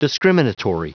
Prononciation du mot discriminatory en anglais (fichier audio)
Prononciation du mot : discriminatory